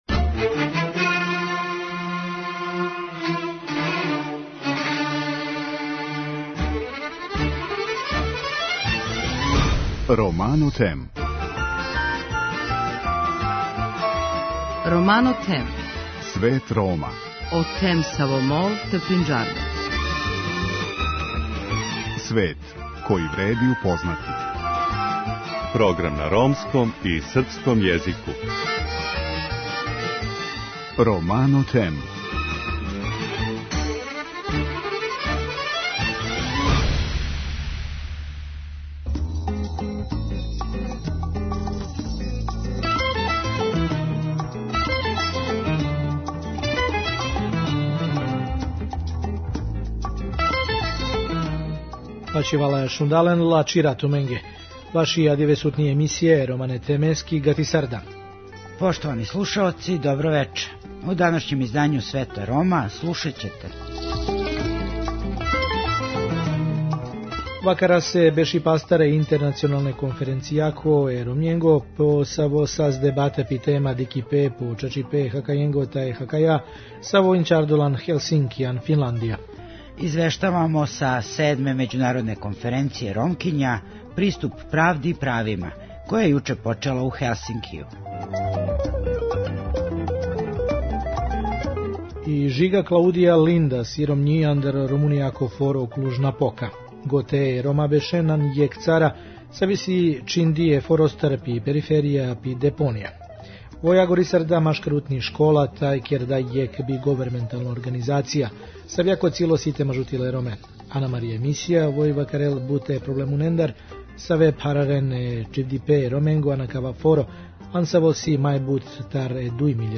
Извештавамо са седме међународне конференције Ромкиња „Приступ правди и правима“ која је јуче почела у Хелсинкију.